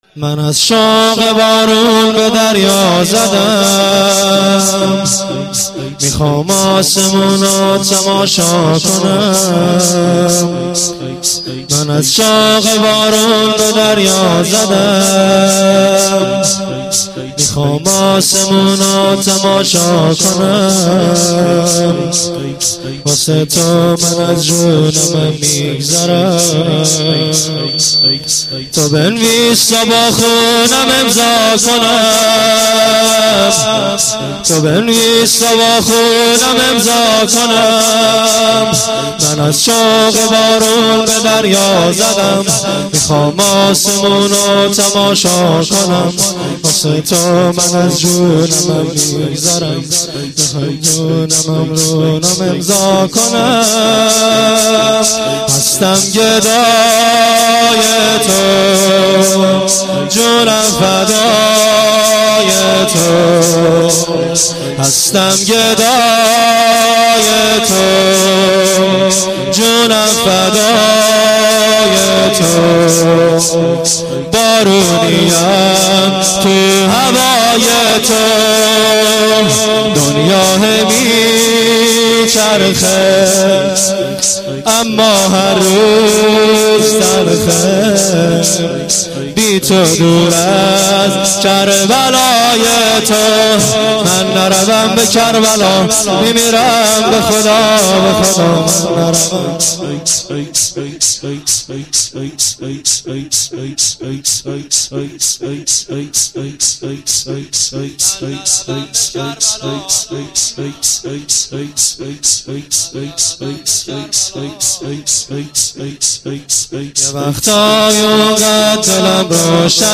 شور - من از شوق بارون به دریا زدم